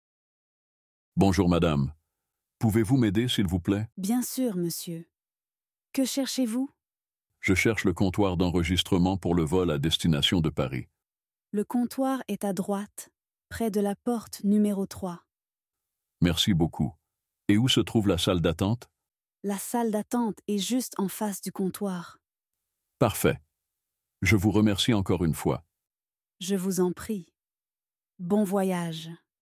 Dialogue en français – Niveau A1/A2